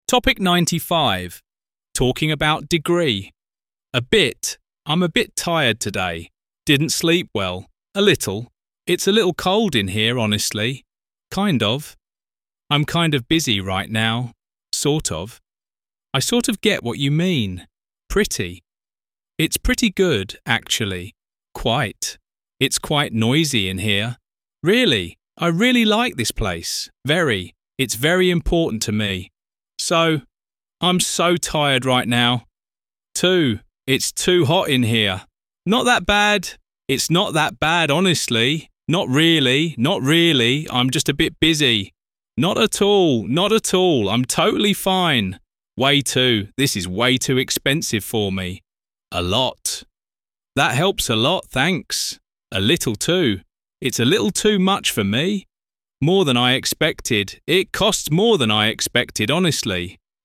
Mỗi từ vựng đều đi kèm câu ví dụ thực tế, được đọc rõ ràng trong file MP3, giúp bạn hiểu và nhớ nhanh từ và vị trí của từ trong câu, biết dùng từ đó ở đâu – dùng như thế nào cho đúng.